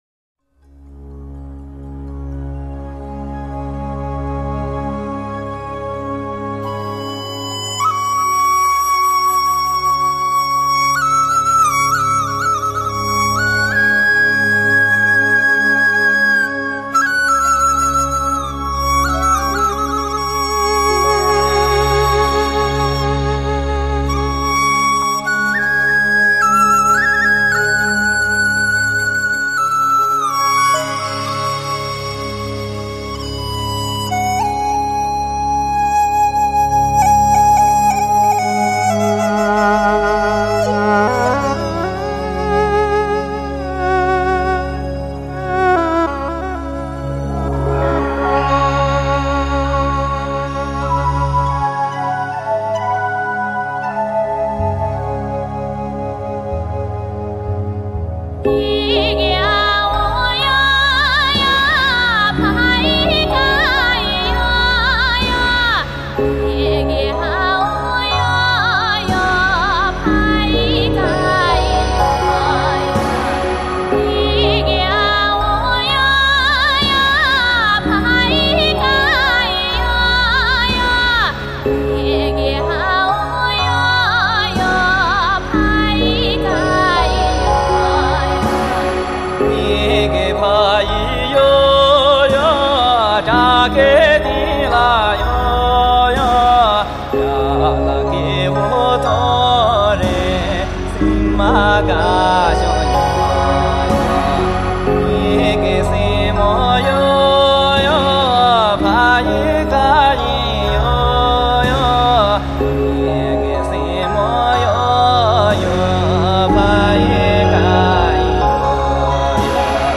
藏族